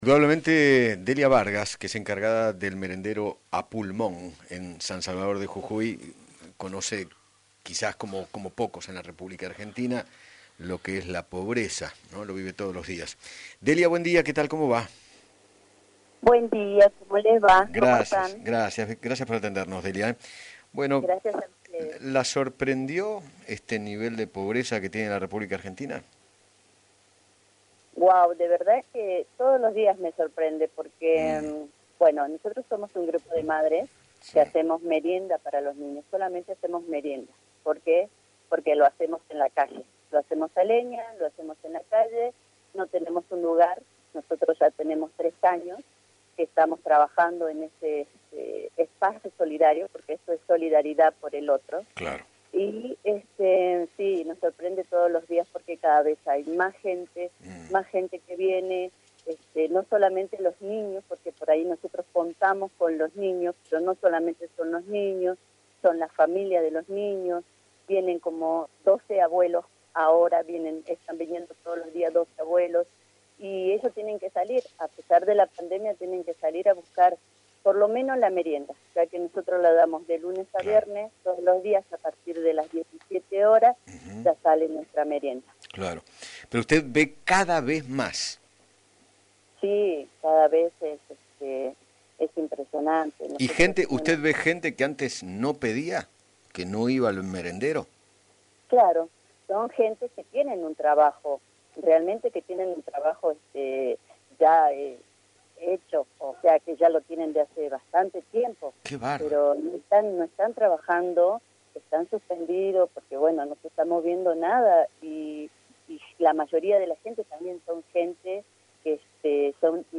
Eduardo Feinmann dialogó con